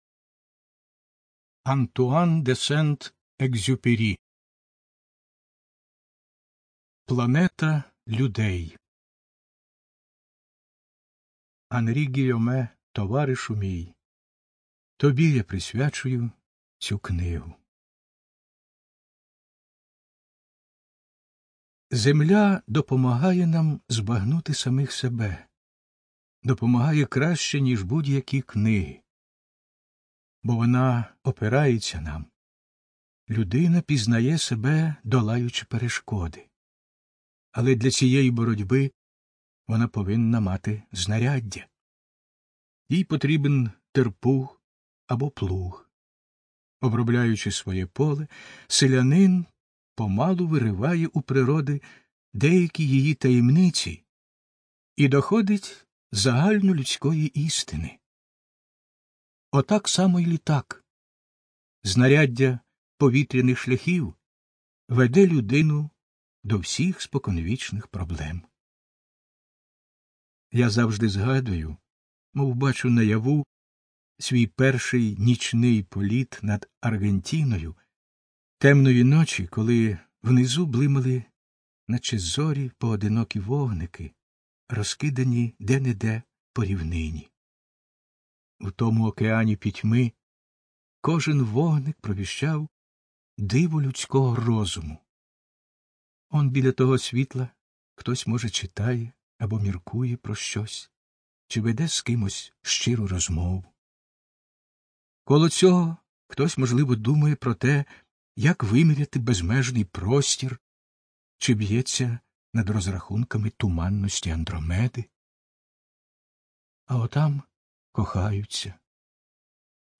ЖанрКниги на языках народов Мира